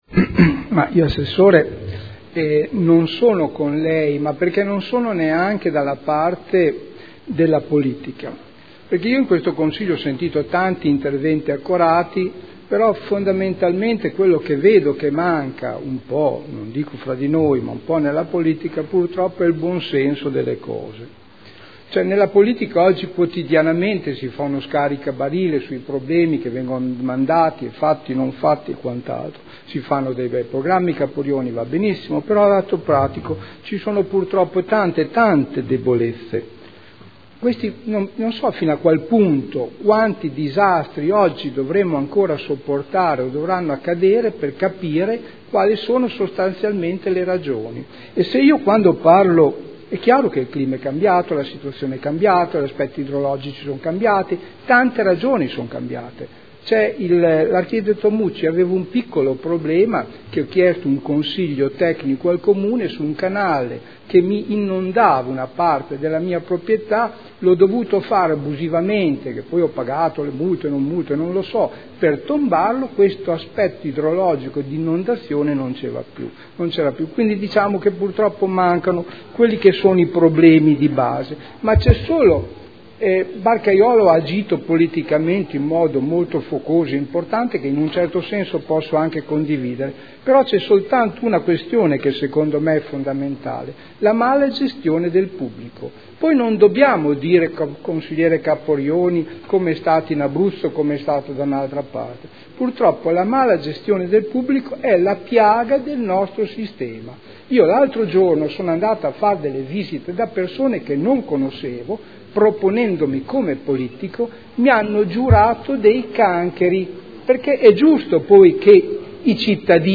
Seduta del 30/01/2014. Dibattito su interrogazioni riguardanti l'esondazione del fiume Secchia.